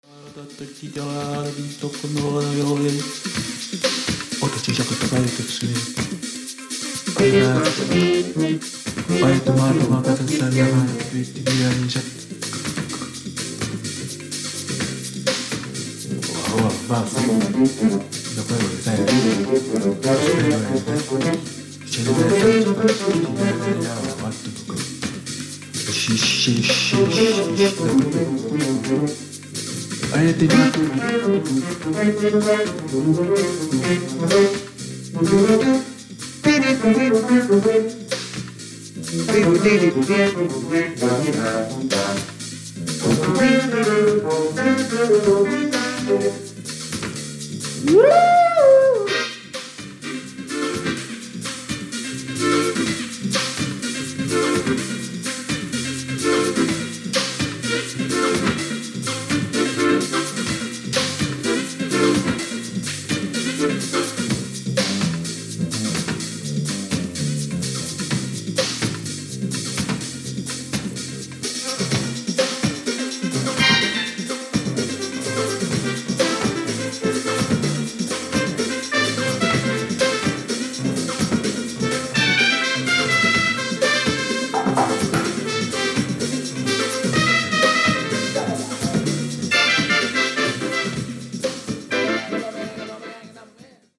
シンセサイザーを使ったJAZZ FUSION!!!